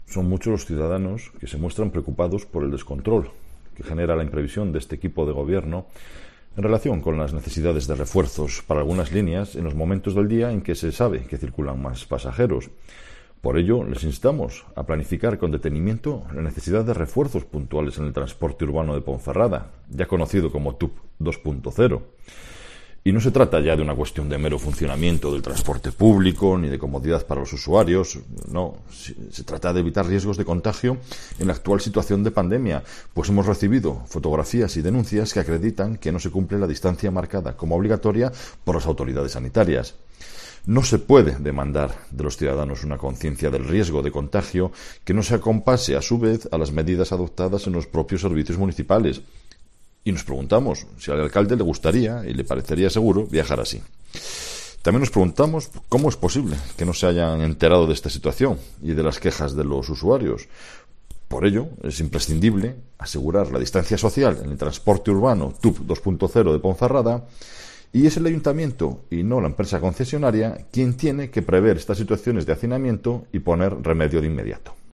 AUDIO: Escucha aquí a Marco Morala, portavoz del PP en la capital berciana